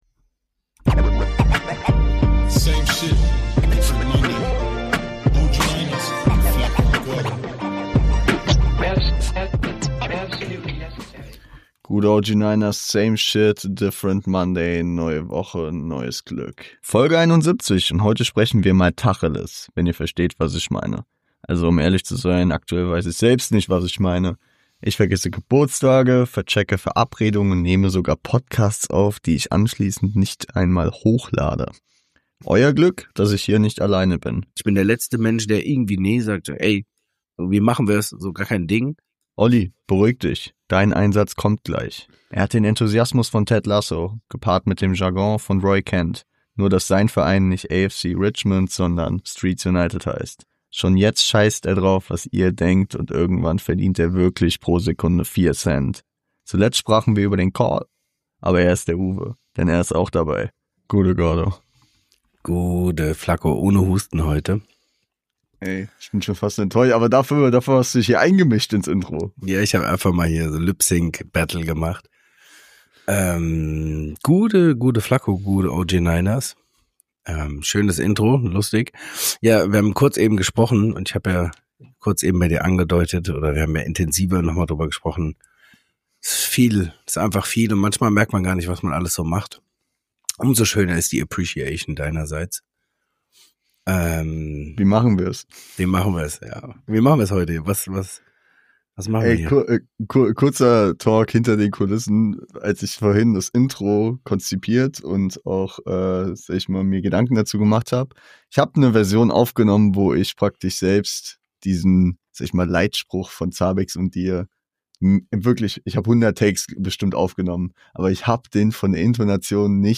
Nach längerer Zeit sind wir mal wieder in gewohntem Habitat; Aufnahme auf einen Freitag Abend und es wird Tacheles geredet.